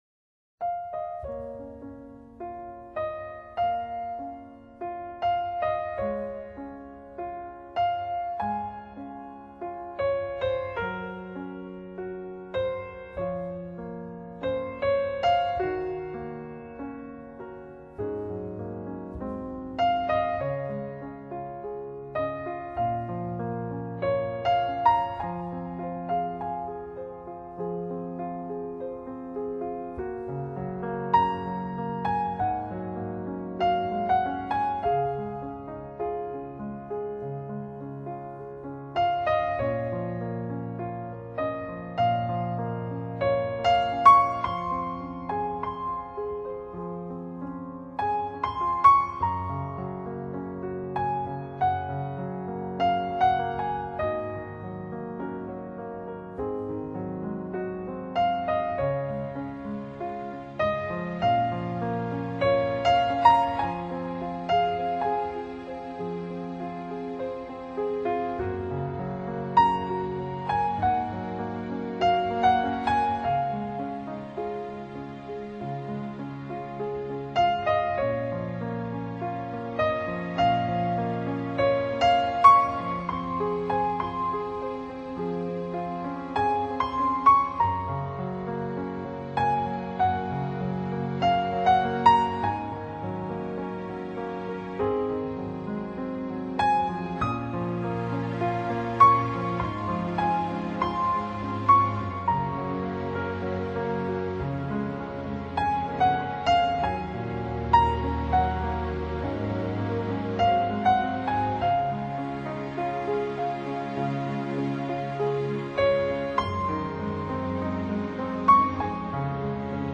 NewAge
的钢琴，散发出活跃、引人深省的聆赏魅力